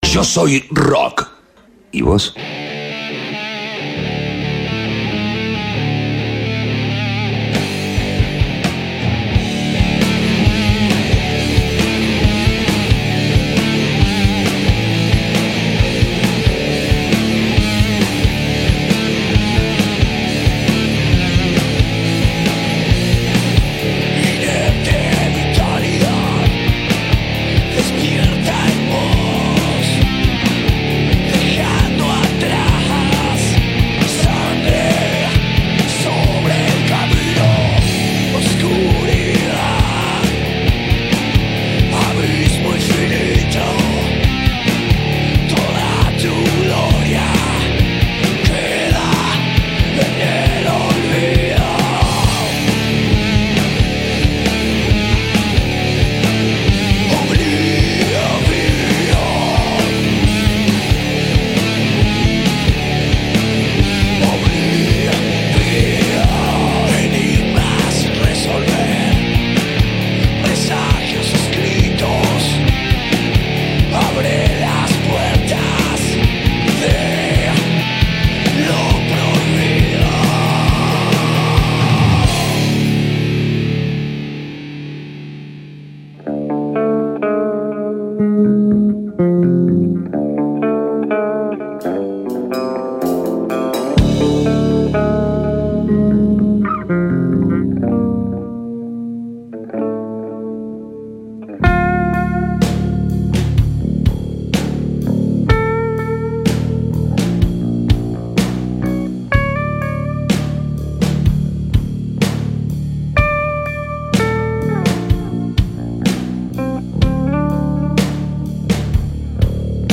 El power-trío de heavy metal marplatense, Sierpes, visitó los estudios de Radio KLA el jueves pasado para una entrevista exclusiva en ''Yo Soy Rock''.